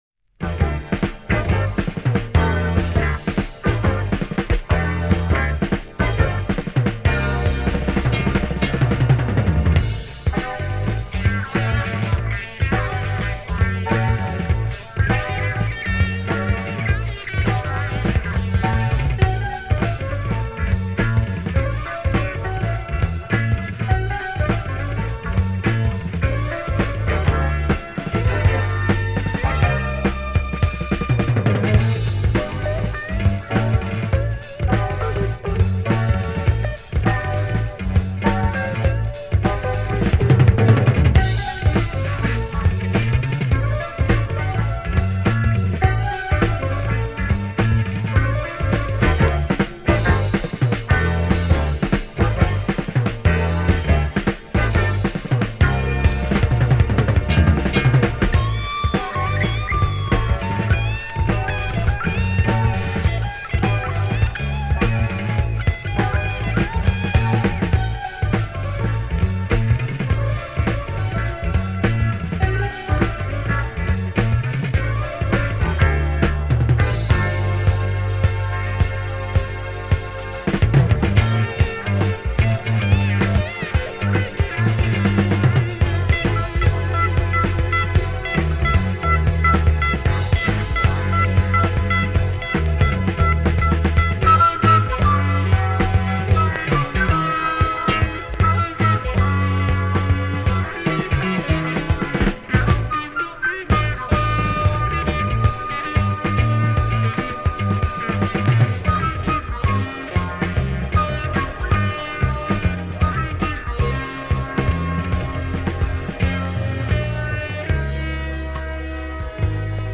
Послушаем немного старенького, танцплощадка - потанцуем, в общем своя старая ностальгическая тема. Записи в большинстве не студийные, любительские из 70х, перемешал с 80ми, да  и музыканты разумеется тоже далеко не все высопрофессиональные, т.ч. без претензий.